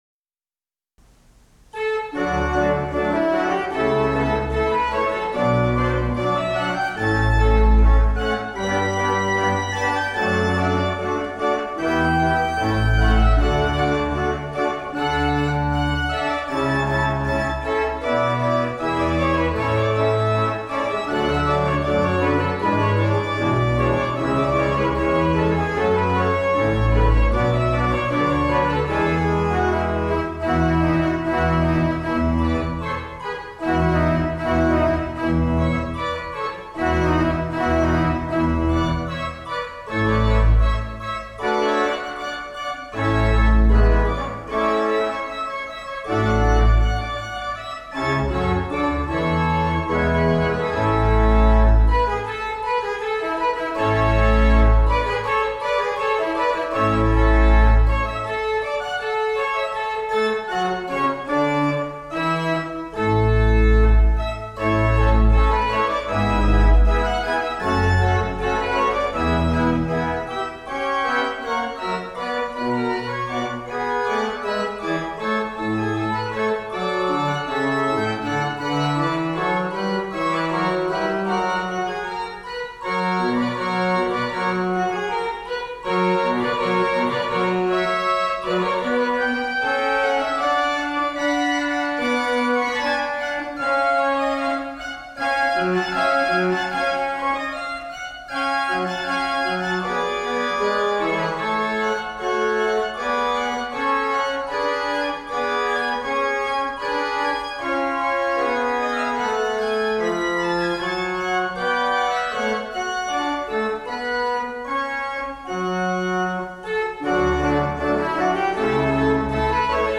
Service of Worship
Postlude